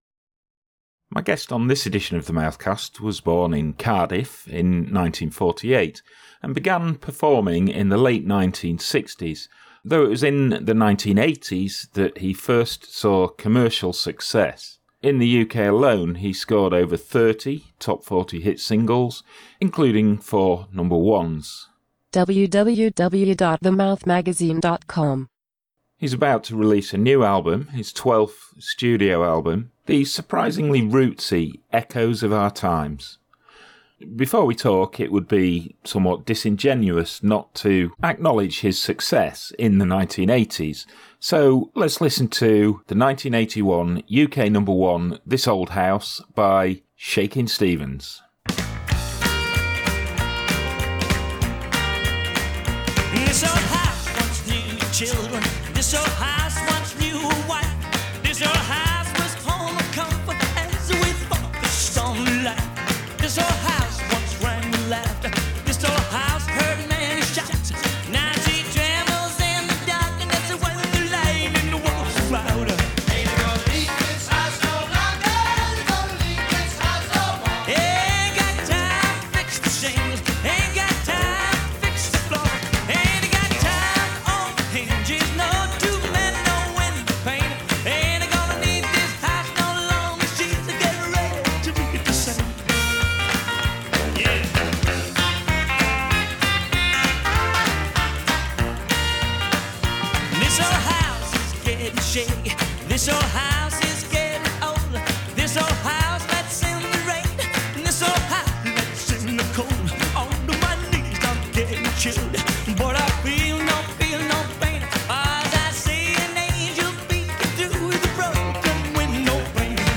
In this new edition of The Mouthcast, Shaky talks about some of these stories, but also looks back on his hugely successful 1980s and his starring role in the late ’70s West End stage musical ELVIS…